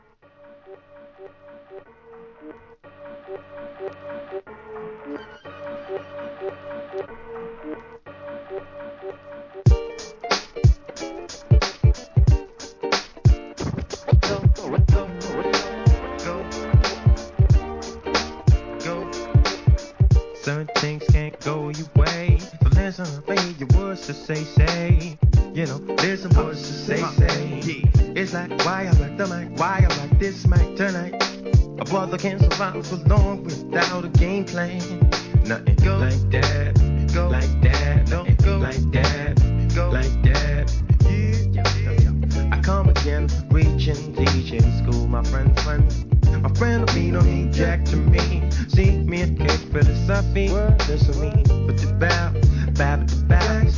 HIP HOP/R&B
ギターの音色が心地よい1999年のソウルフルなR&B!